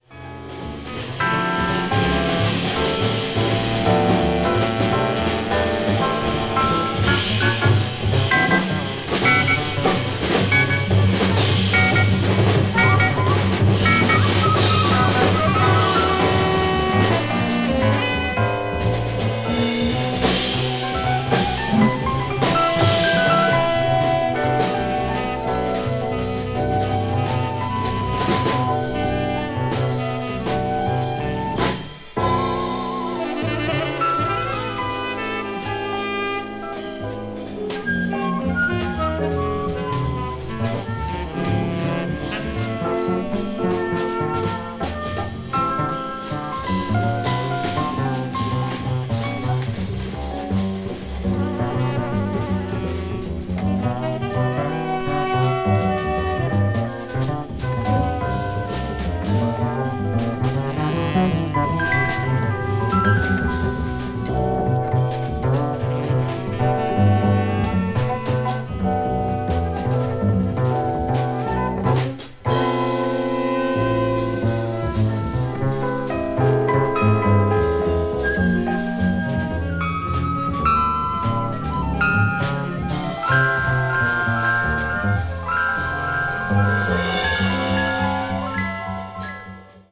avant-jazz   contemporary jazz   post bop   spritual jazz